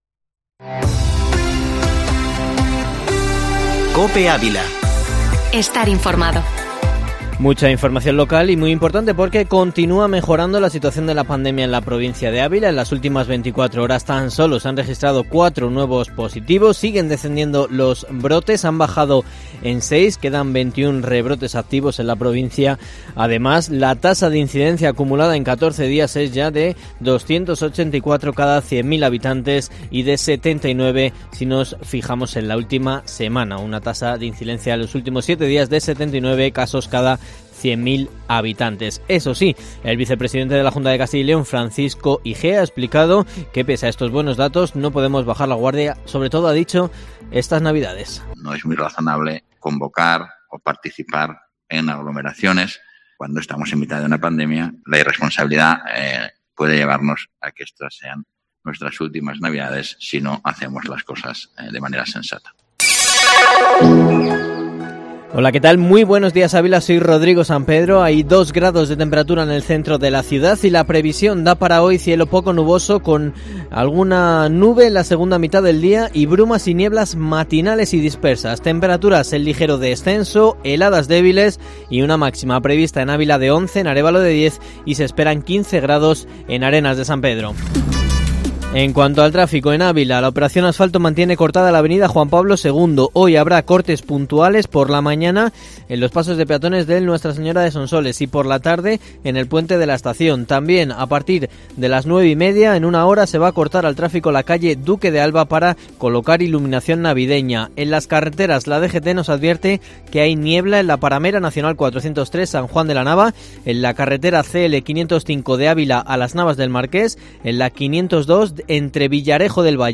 Informativo matinal Herrera en COPE Ávila 01/12/2020